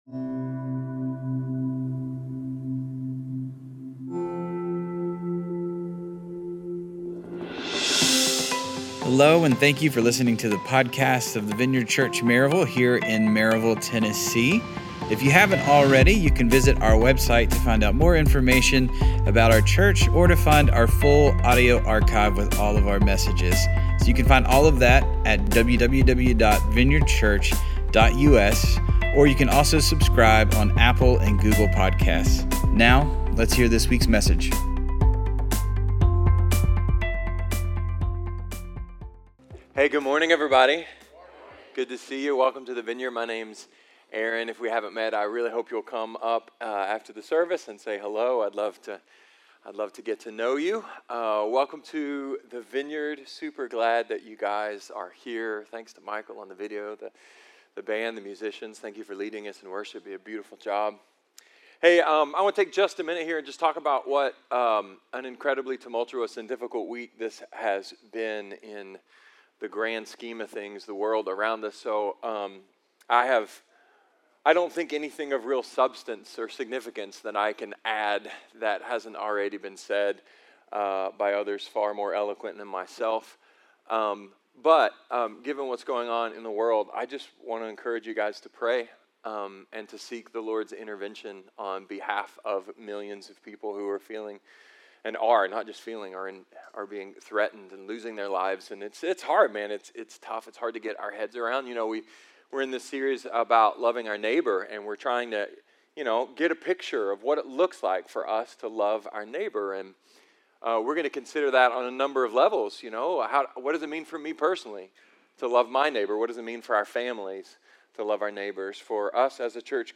A sermon about getting paid, what’s been paid for us, and paying it forward.